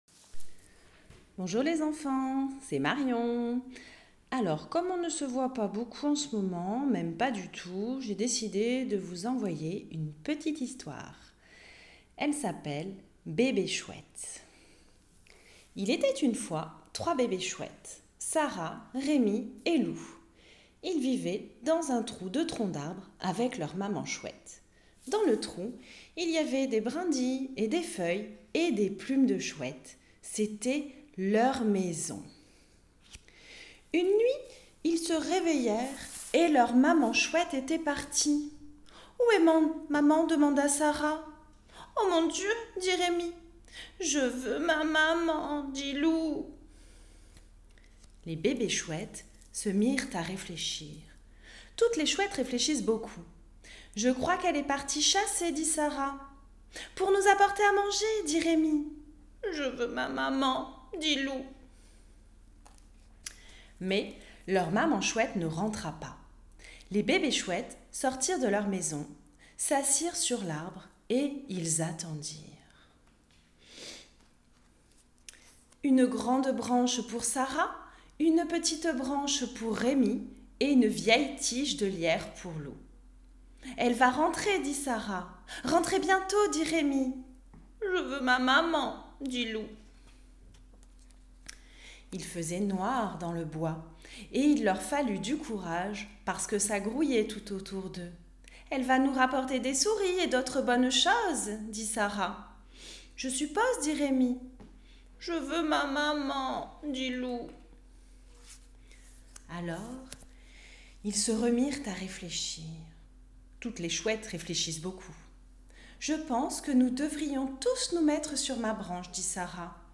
Une sélection de différents contes audio et histoires animées, vous sont proposés. Contés avec entrain et dynamisme